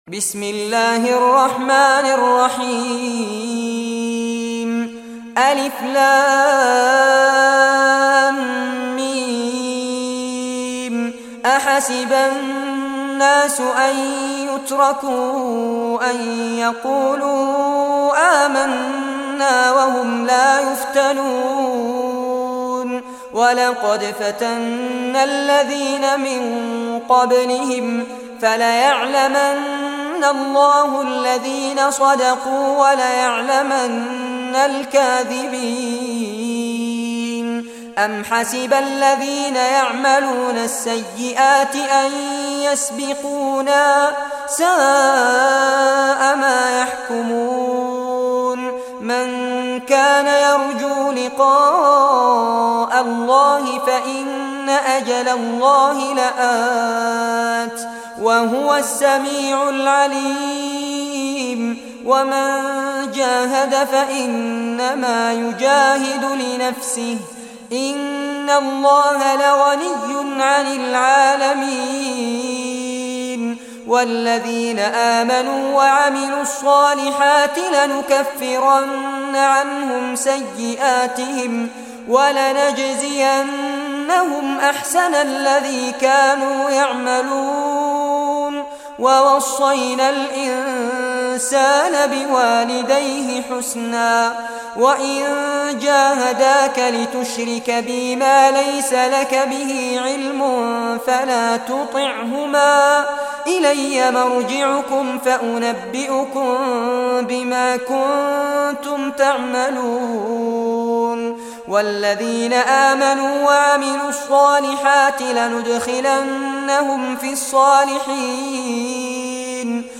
Surah Al-Ankabut Recitation by Fares Abbad